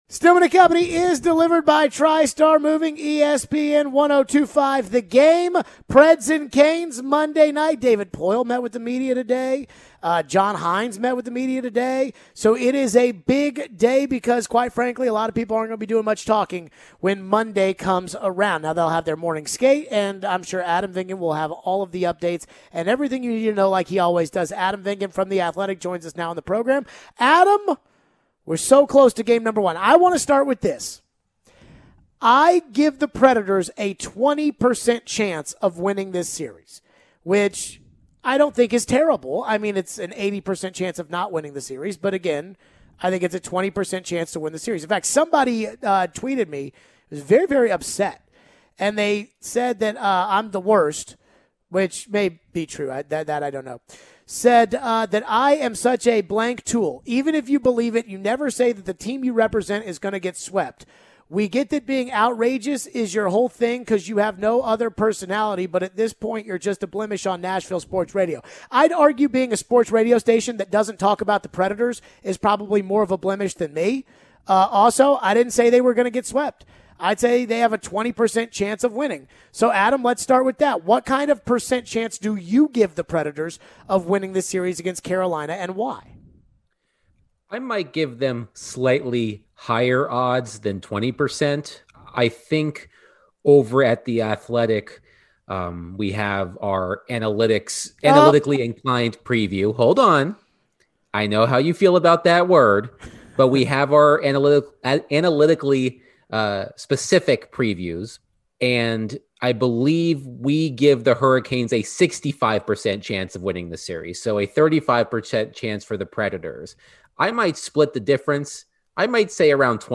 We take your calls and texts on the Preds involving the expansion draft, the herd line, and Rocco Grimaldi.